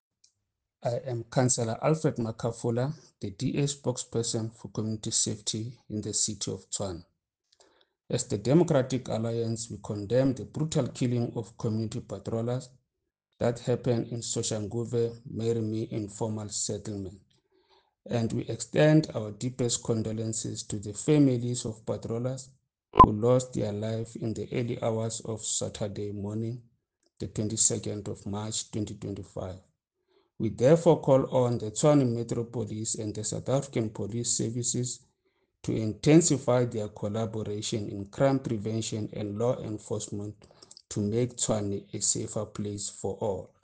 Note to Editors: Please find the attached soundbites in